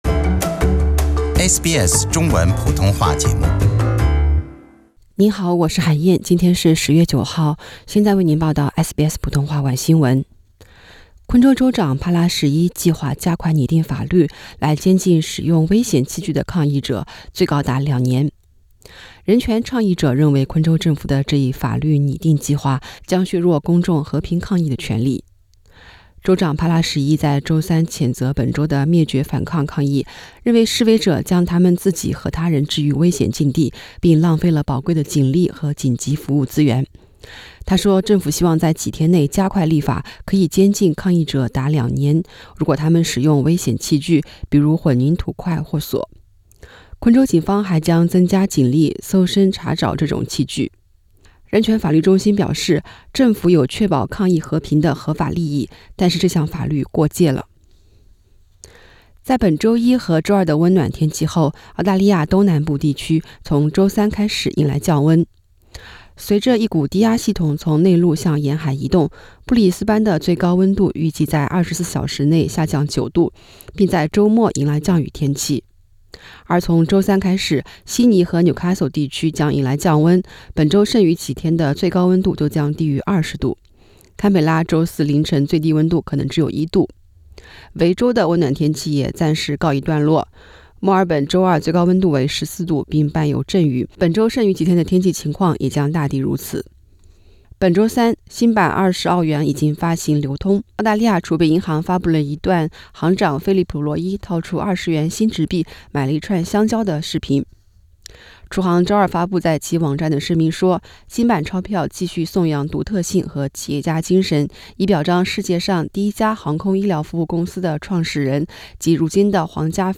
SBS晚新闻（10月9日）